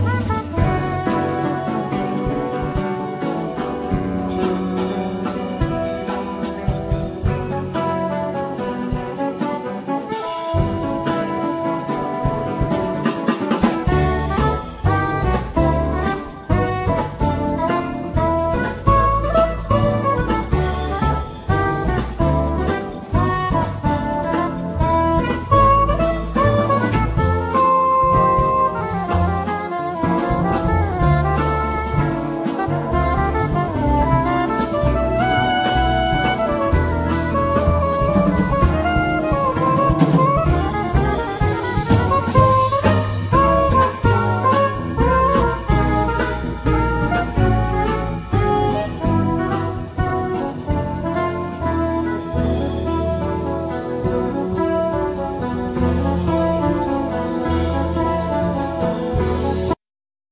Acoustic bass
Drums, Percussions